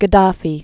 (gə-däfē), Muammar al-